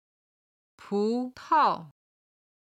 軽声の音は音源の都合上、四声にて編集しています。
葡萄　(pú tao)　ブドウ
30-pu2tao5.mp3